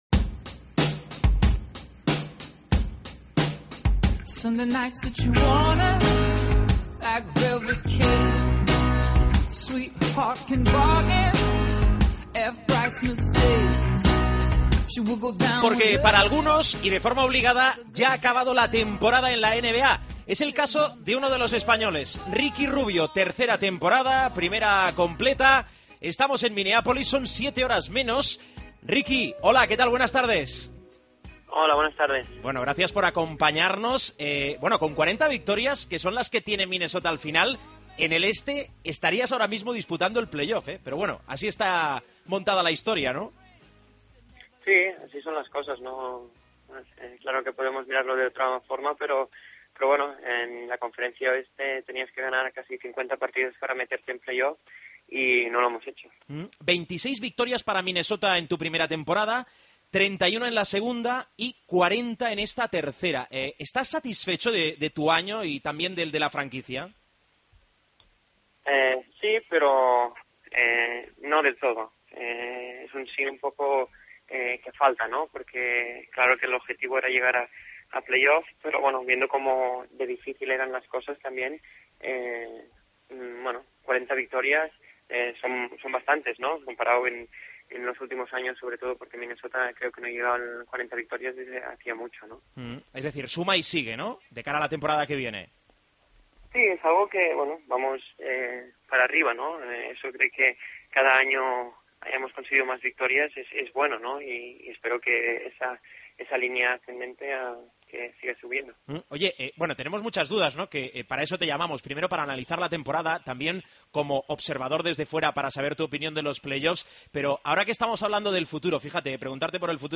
Entrevista a Ricky Rubio en Showtime